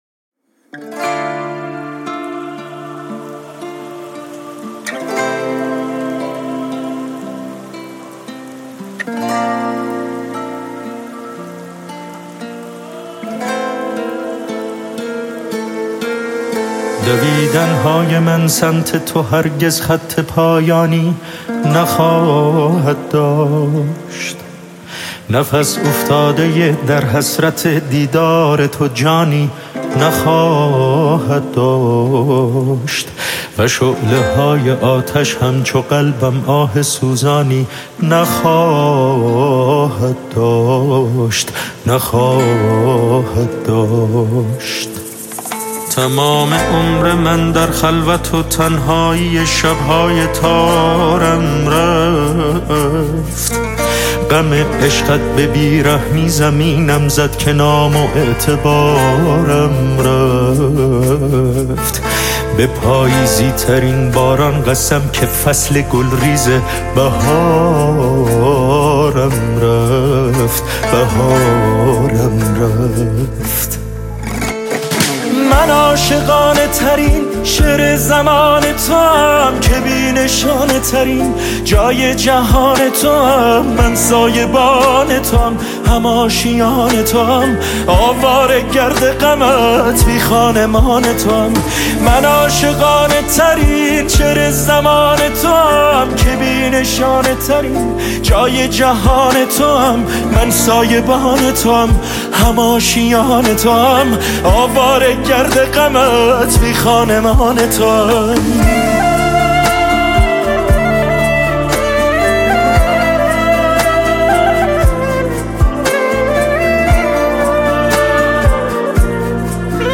پاپ غمگین عاشقانه غمگین